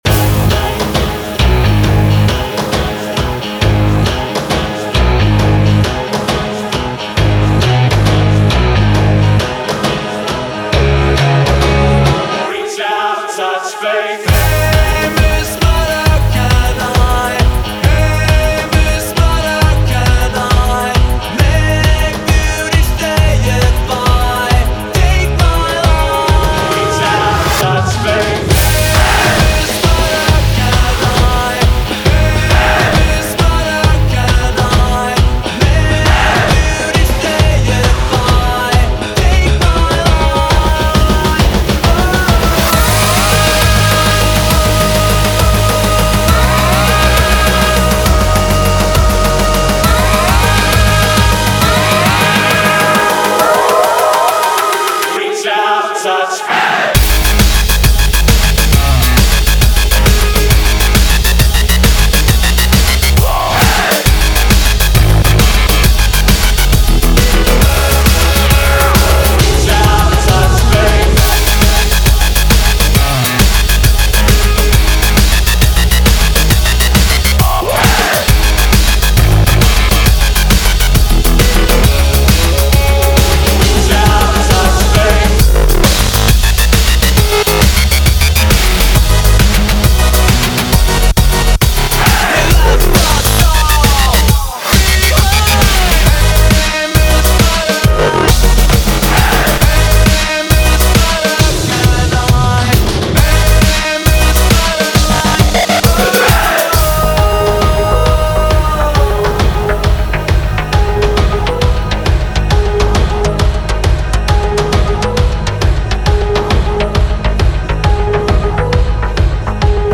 BPM135
Electro Mash-Up